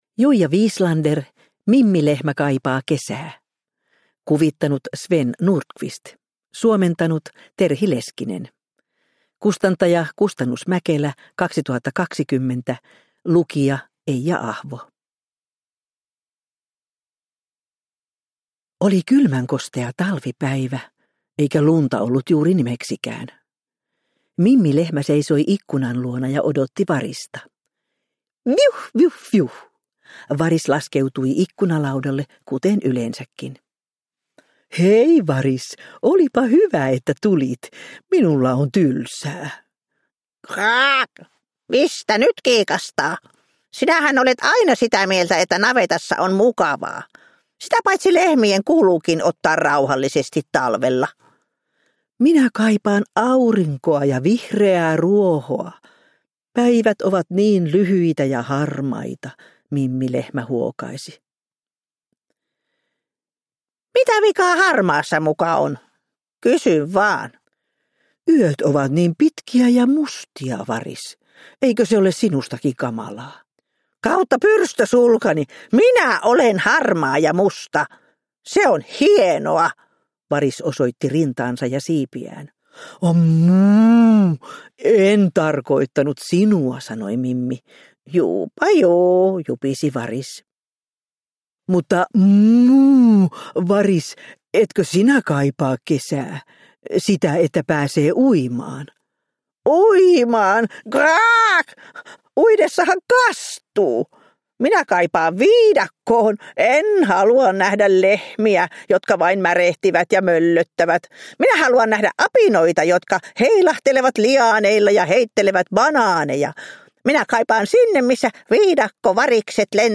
Mimmi Lehmä kaipaa kesää – Ljudbok – Laddas ner